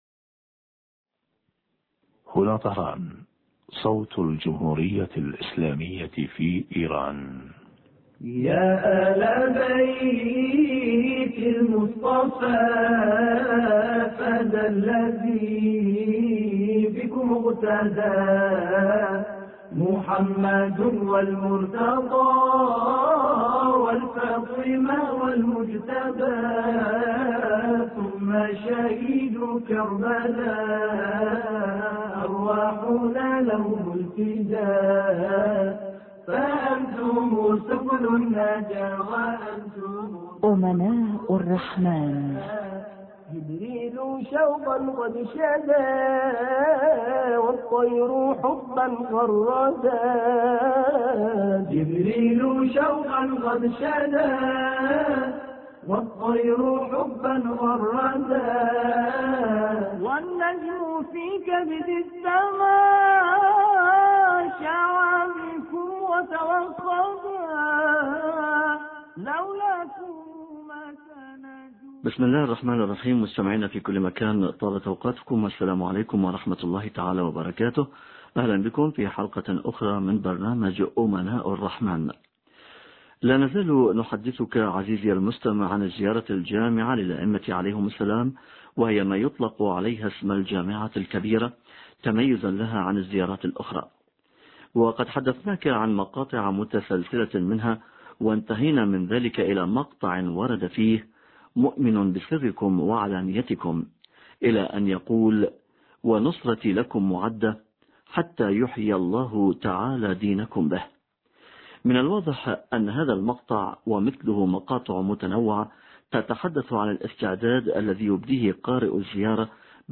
في الاتصال الهاتفي التالي